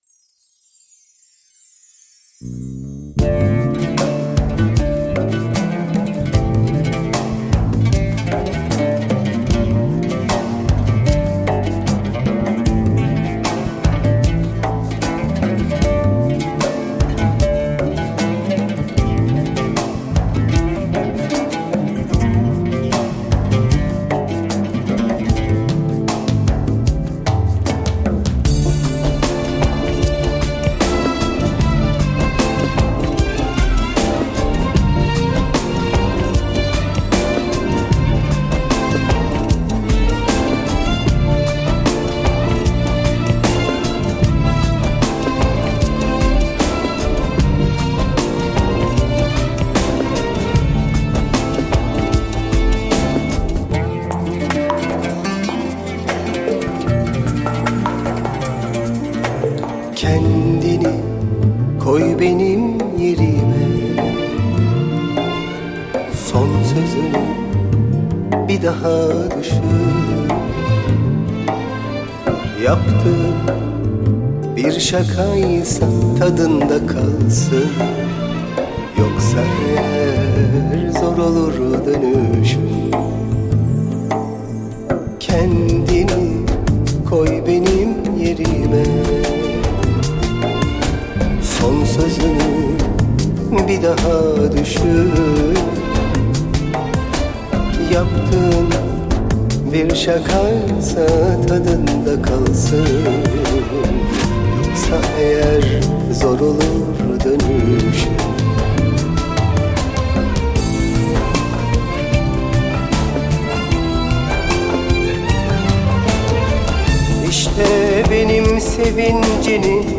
çift oktav şarkı
Eser Şekli : Pop Fantazi